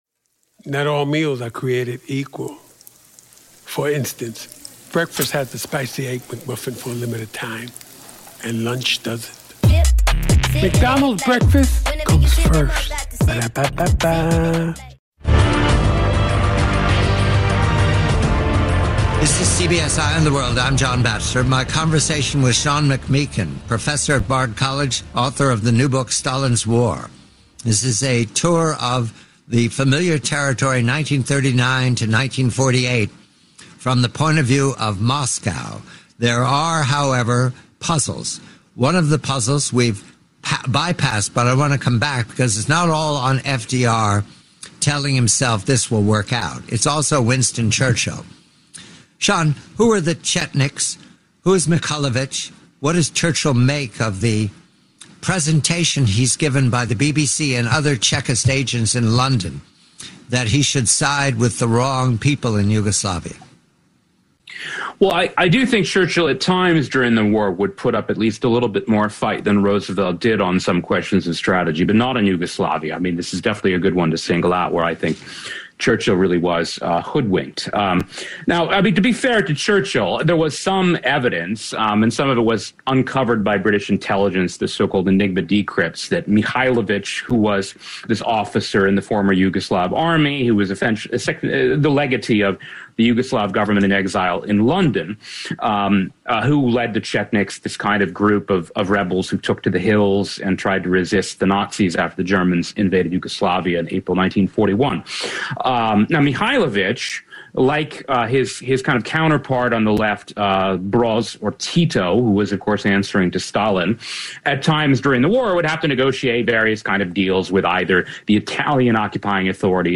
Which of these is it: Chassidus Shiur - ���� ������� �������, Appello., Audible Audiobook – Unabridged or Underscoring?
Audible Audiobook – Unabridged